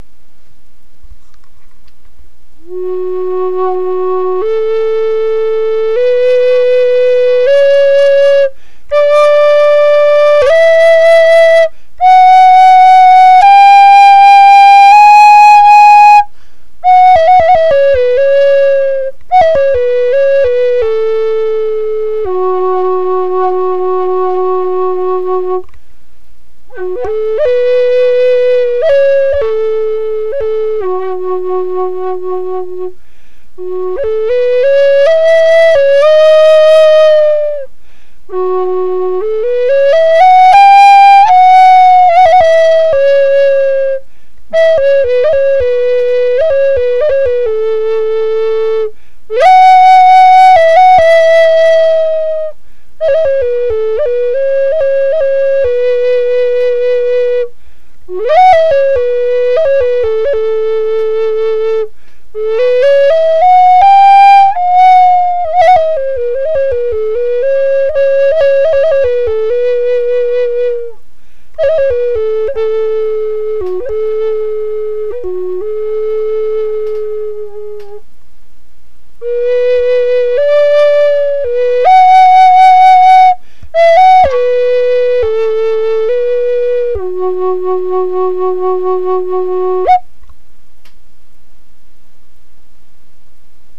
Woodland-Style Native American Flute F# Minor
This Woodland-Style Native American flute is tuned to the key of F# and wasÂ lovingly created in the Magic Wind workshop.
Curly Maple, Zebra and Walnut